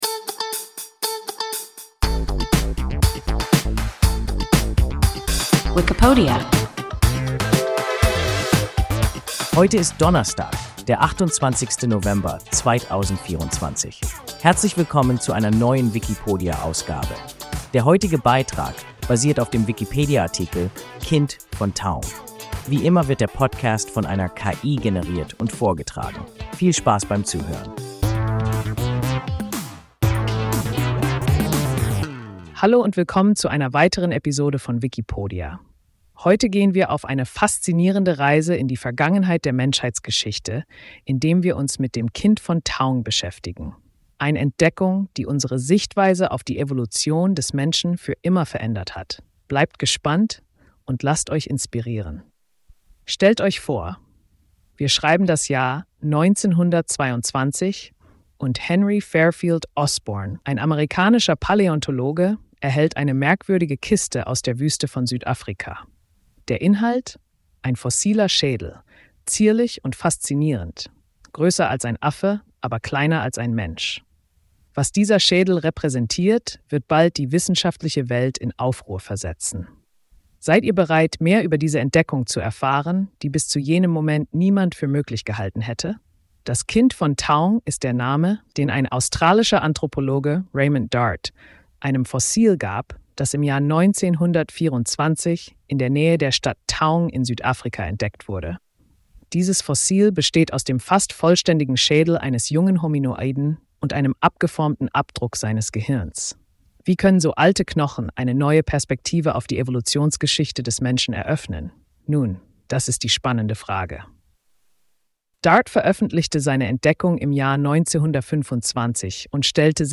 Kind von Taung – WIKIPODIA – ein KI Podcast